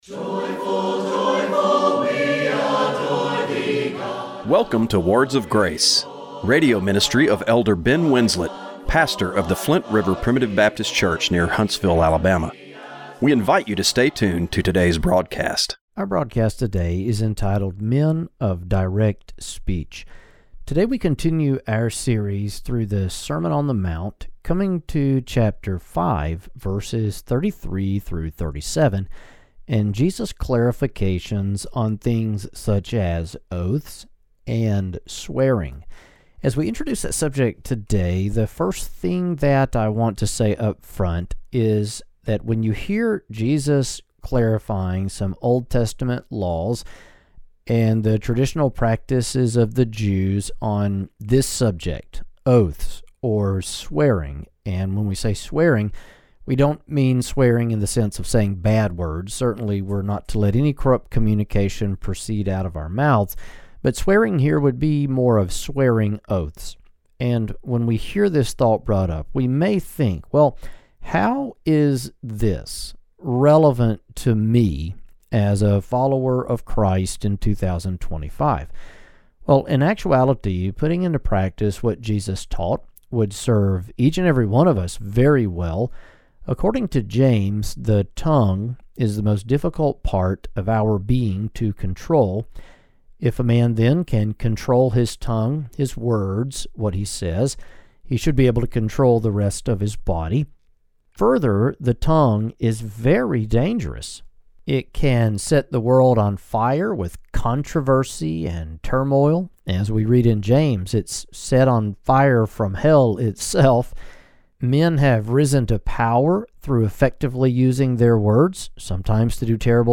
Radio broadcast for June 15, 2025.